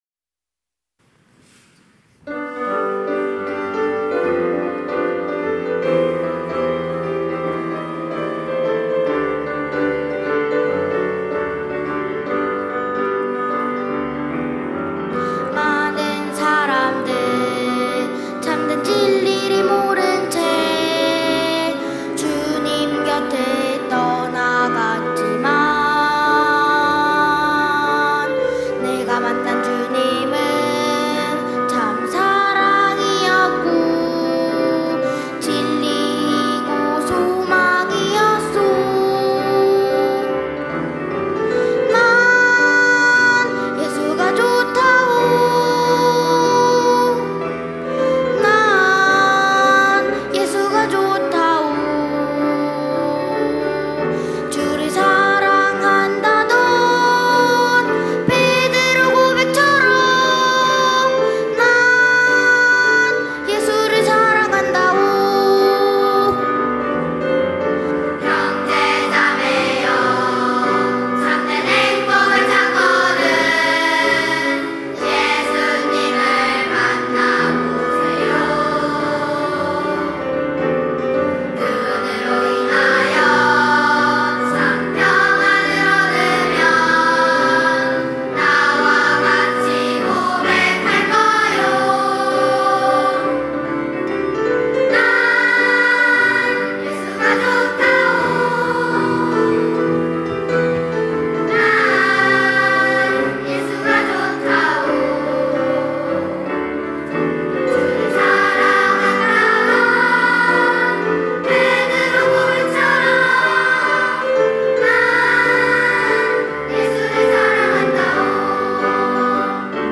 특송과 특주 - 난 예수가 좋다오, 예수님 찬양
초등부연합찬양대